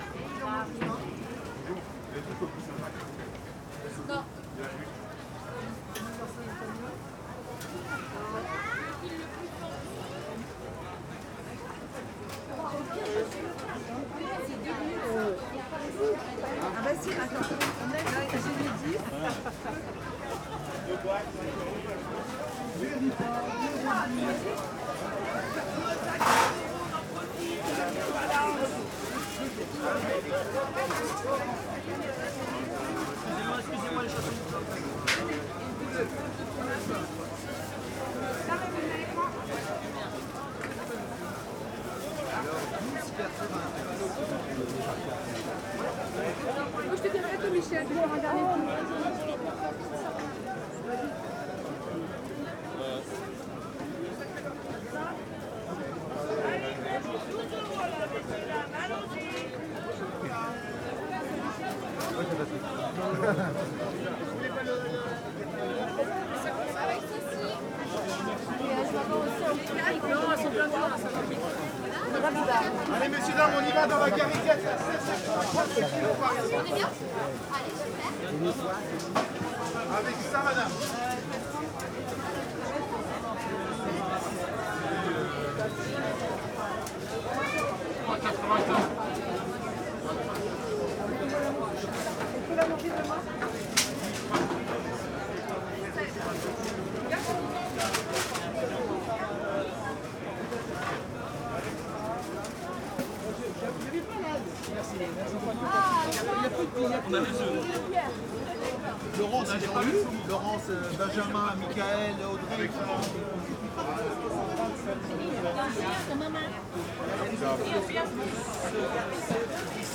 Outdoor Market #2
Market day in Neuilly-sur-Seine. People speak French and I wander between the stalls.
UCS Category: Ambience / Market (AMBMrkt)
Type: Soundscape
Channels: Stereo
Disposition: ORTF
Conditions: Outdoor
Realism: Realistic
Equipment: SoundDevices MixPre-3 + Neumann KM184